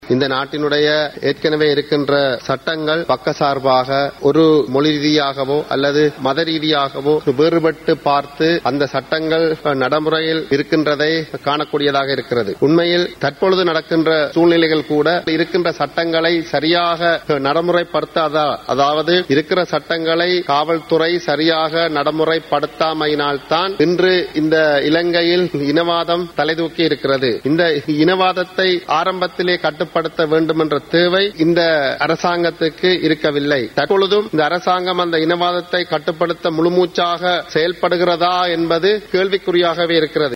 இது தொடர்பான விவாதத்தில் கலந்து கொண்டு உரையாற்றிய போதே அவர் இதனை தெரிவித்தார்.
குரல் சால்ர்ஸ்